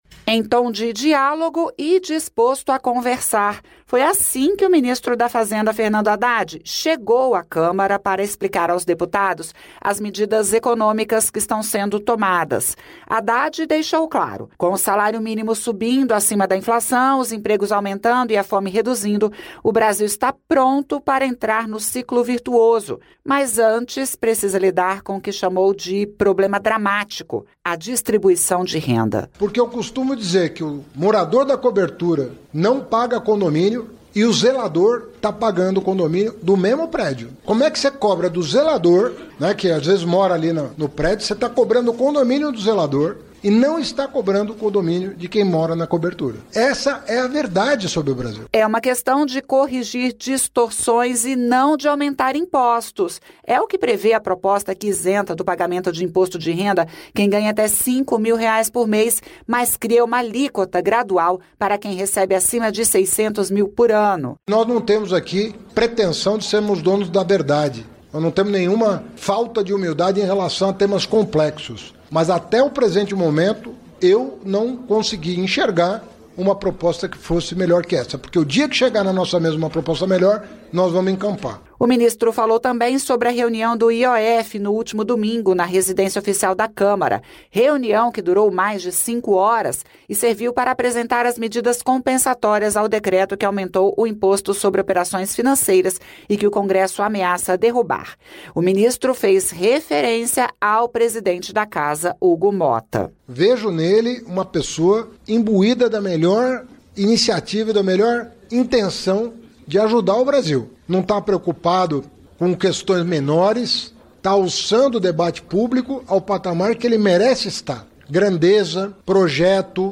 Repórter da Rádio Nacional